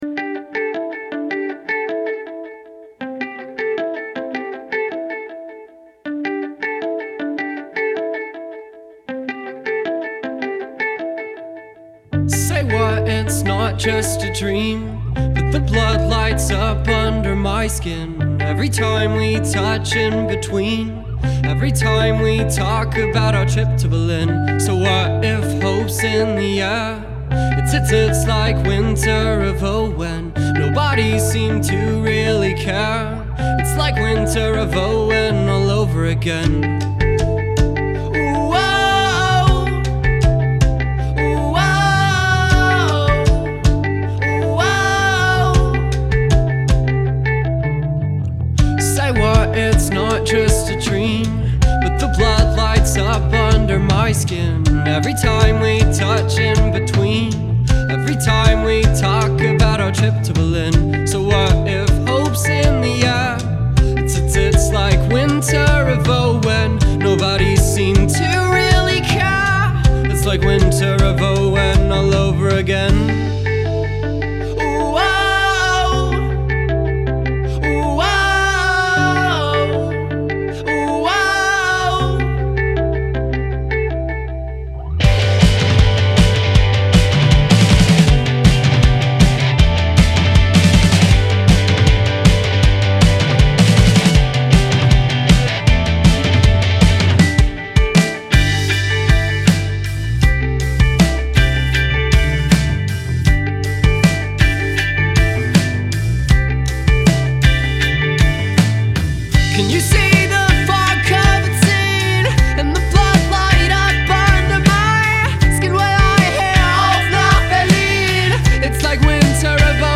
This one is a free remix of German indie group